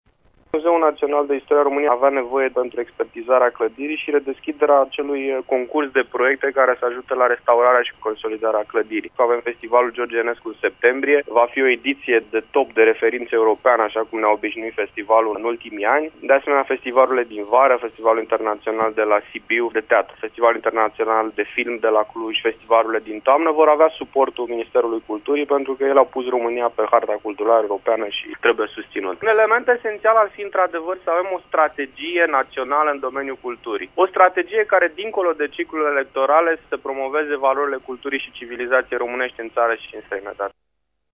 Declaraţia a fost făcută în această dimineaţă, la Radio România Actualităţi, unde Ionuţ Vulpescu şi-a exprimat satisfacţia că bugetul Ministerului pe acest an a fost suplimentat pentru a permite organizarea unor mari evenimente şi proiecte: